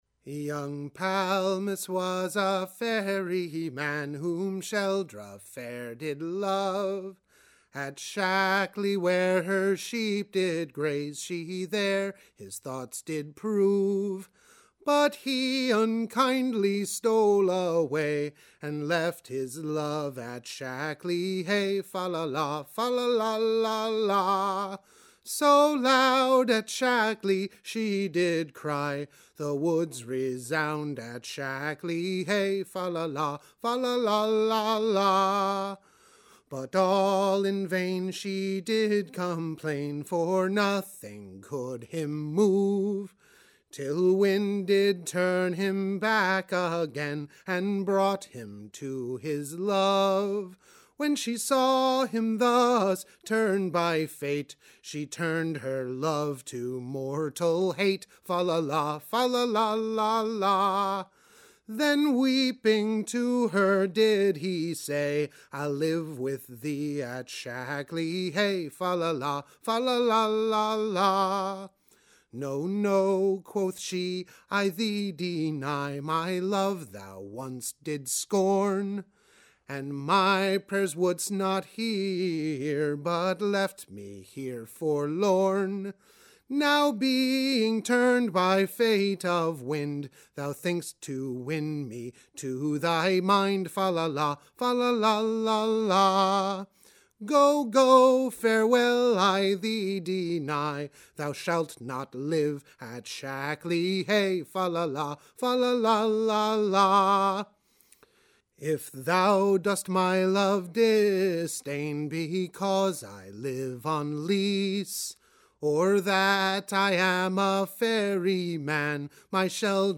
“Palmus and Sheldra” ballad
extended ten (as opposed to just eight) line version of the tune, with the refrain repeated